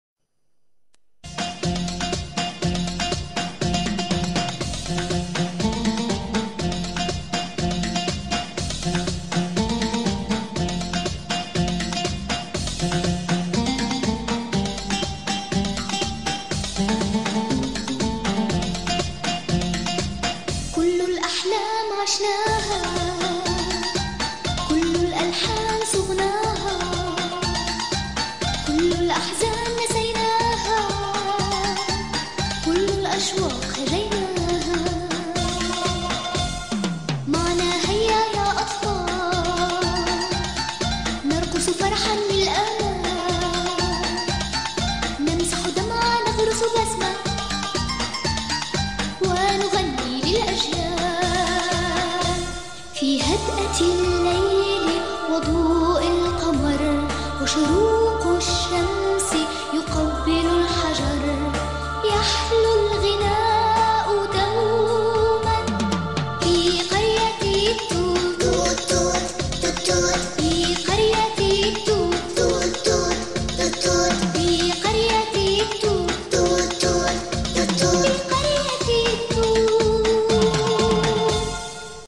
قرية التوت - الحلقة 1 مدبلجة